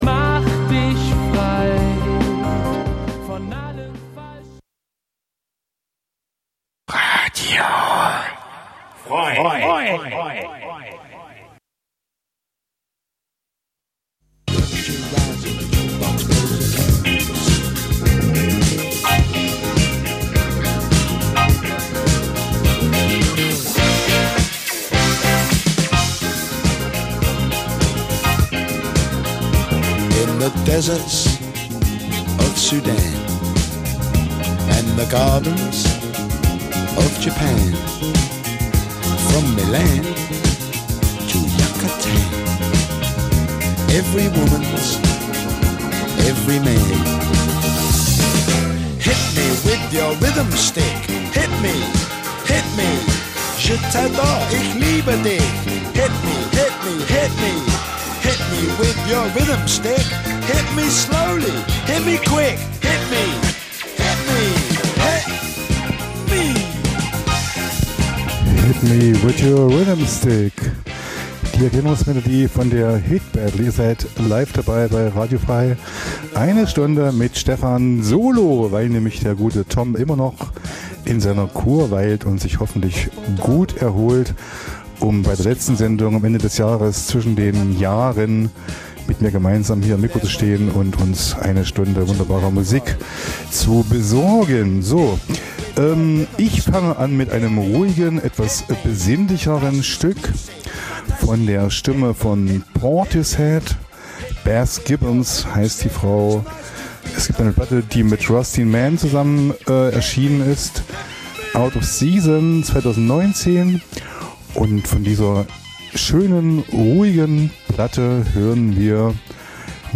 Zwei Musikfreunde duellieren sich mit raren Rock- und Punklegenden... - vinyl meets mp3... crossover zwischen den Welten, Urlaubsmusik und Undergroundperlen. Abgr�nde Ihres Musikgeschmacks tun sich scheinbar unkontrolliert auf.